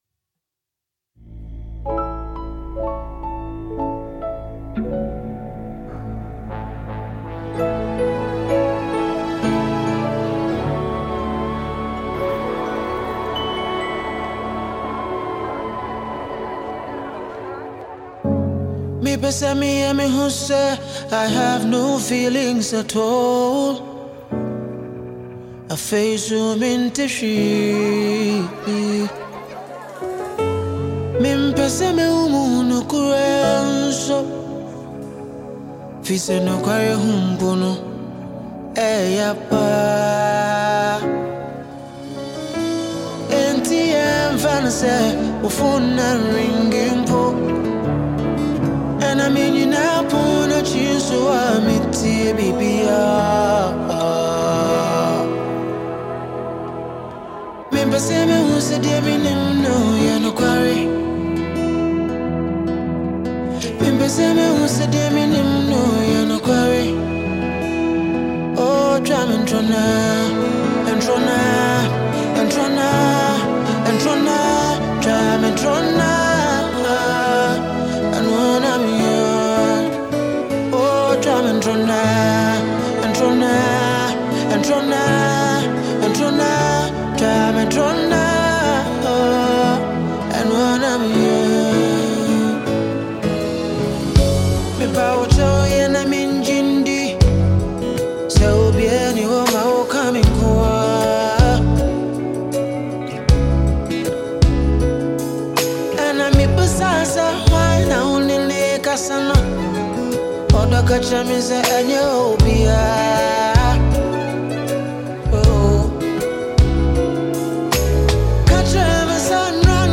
Ghanaian Highlife